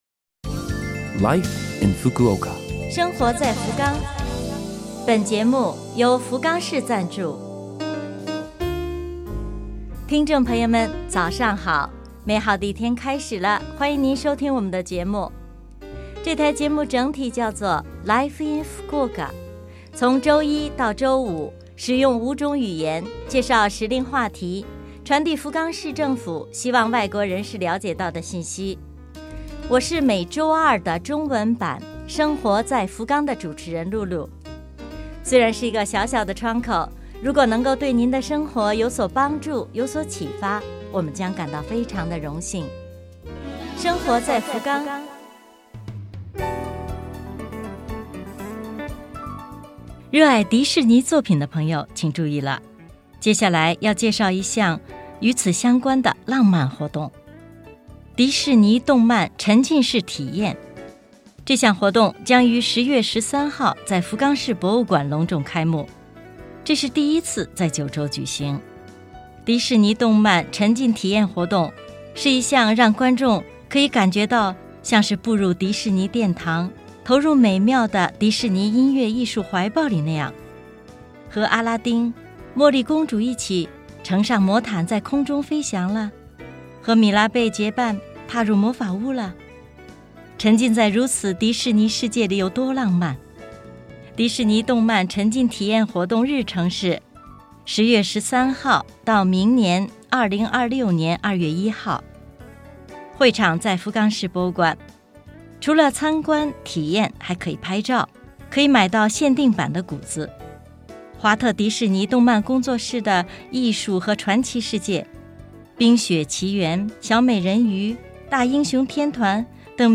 #286 嘉宾访谈